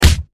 punch4.ogg